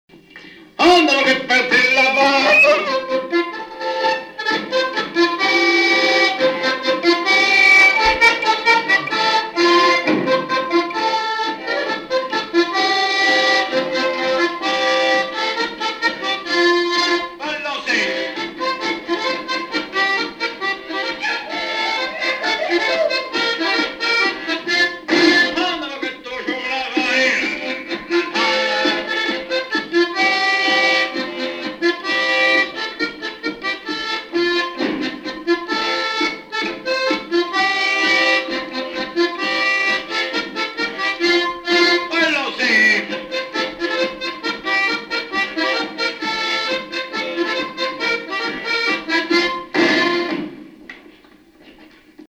Mémoires et Patrimoines vivants - RaddO est une base de données d'archives iconographiques et sonores.
Quadrille - Avant deux
Catégorie Pièce musicale inédite